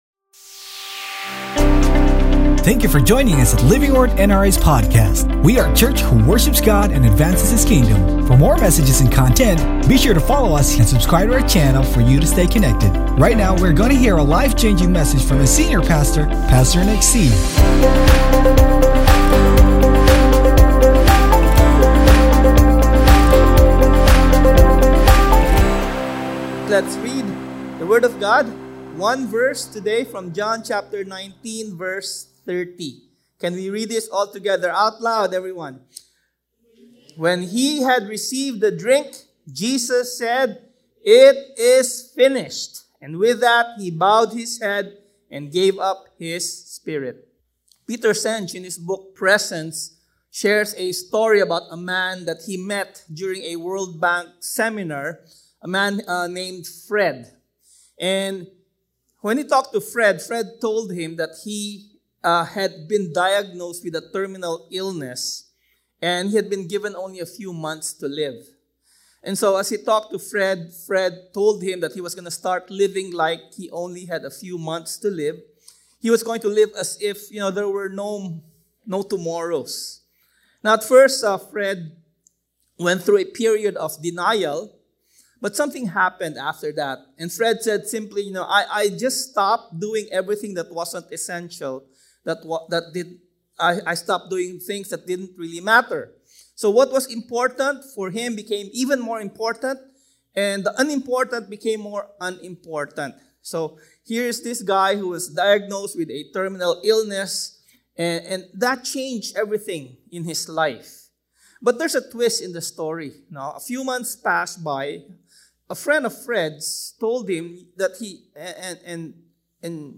Sermon Title: THE BORROWED TOMB Scripture Text: JOHN 19:30 Sermon Notes: JOHN 19:30 NIV 30 When he had received the drink, Jesus said, “It is finished.”